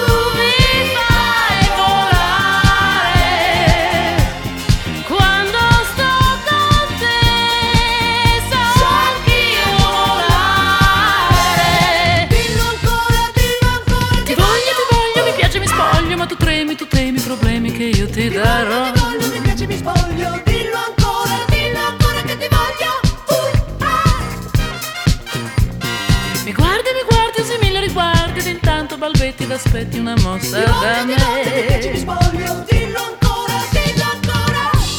Жанр: Поп / Рок